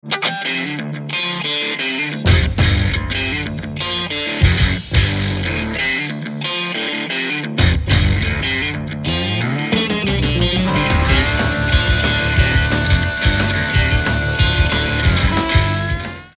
rock instrumental à la guitare
Guitare électrique
Batterie
Basse